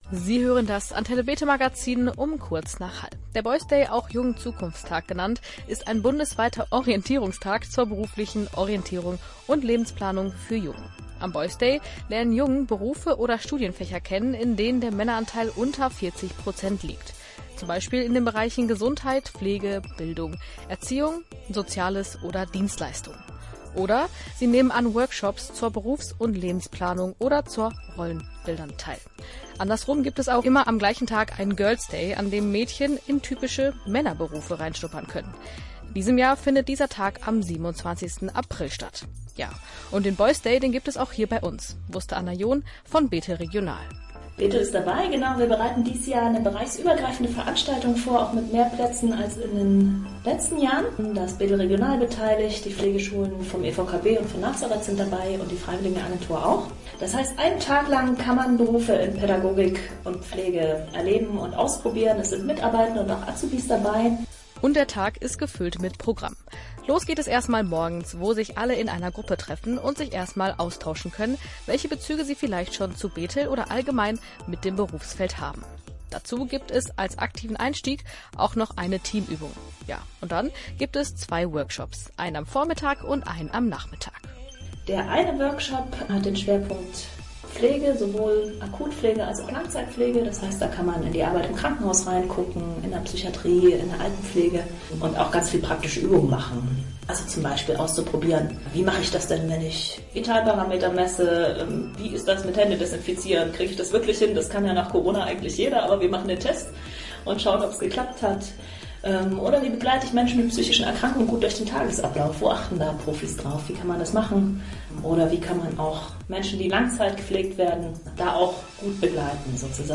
In unserer Sendung vom 3. April 2023 berichteten wir über die Aktion.